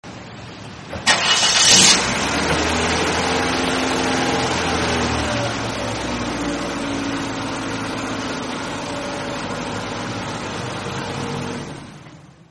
Dzwonki Car Engine
Kategorie Efekty Dźwiękowe